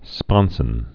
(spŏnsən)